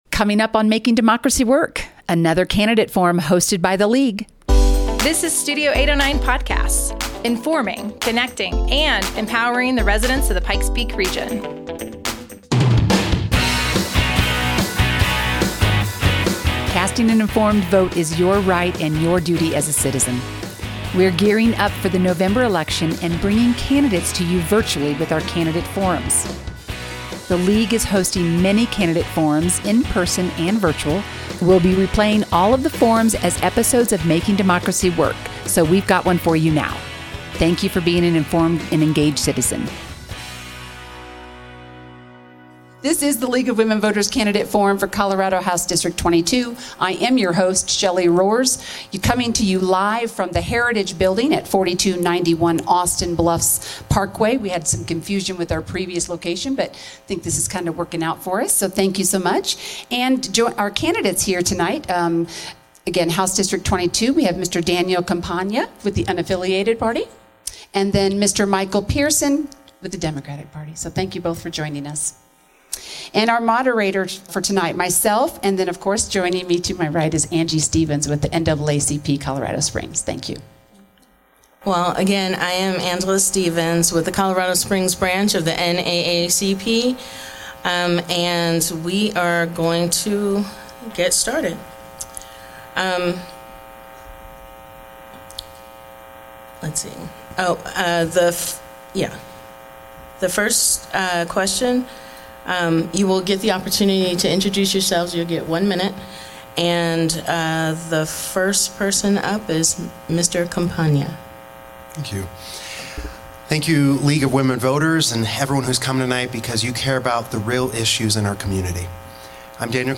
Colorado House District 22 Candidate Forum 2024